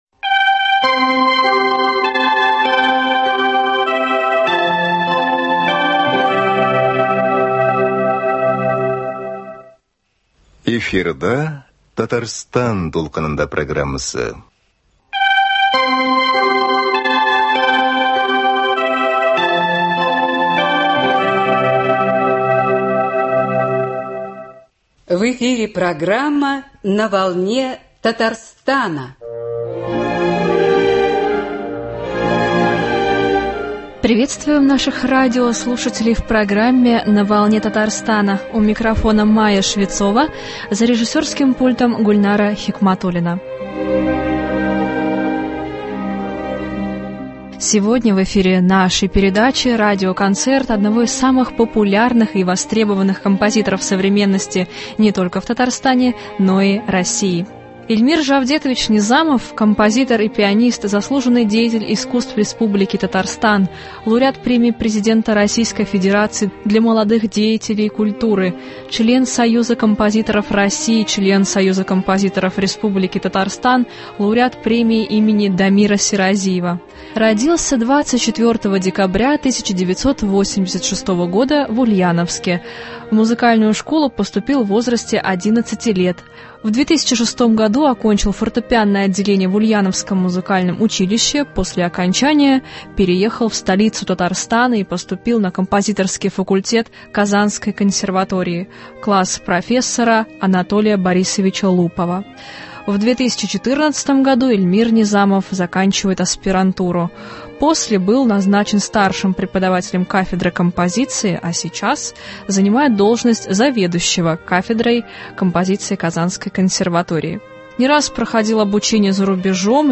Радиоконцерт